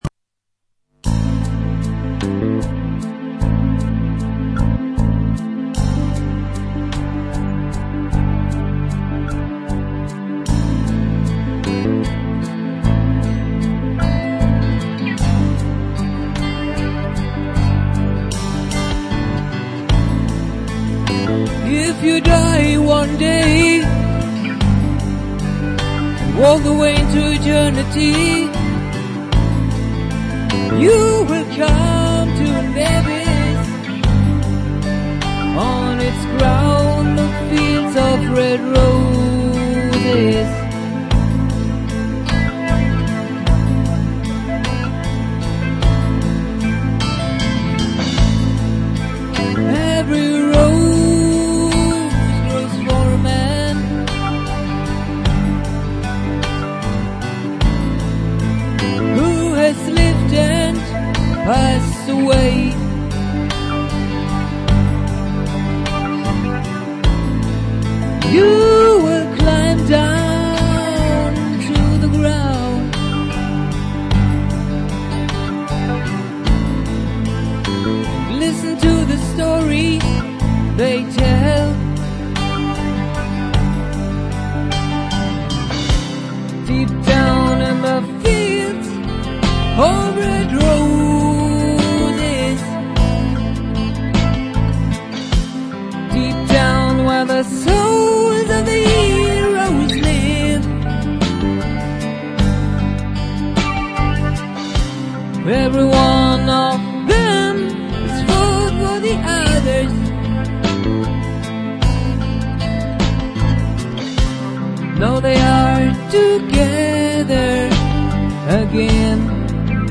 TypLP (Studio Recording)